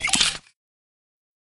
sniper_reload_01.ogg